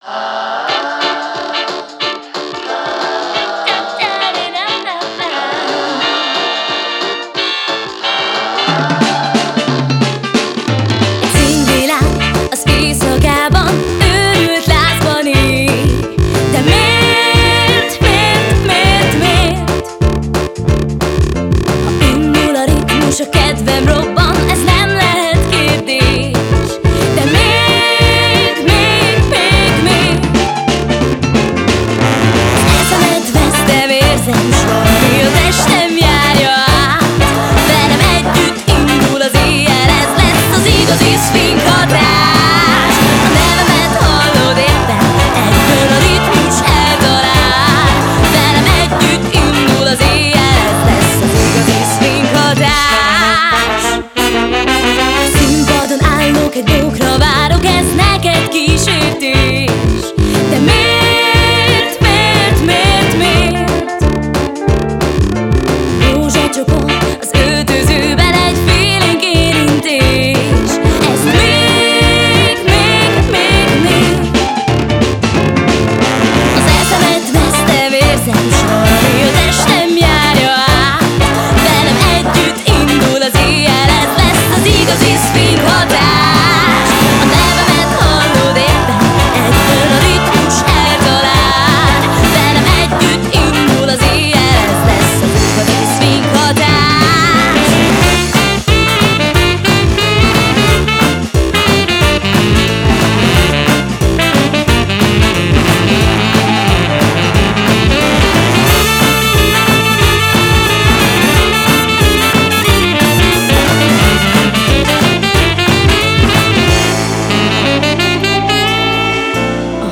Természetesen és nem meglepő módon a dal a jazz egyik műfajának jegyében született meg: és ez a szving.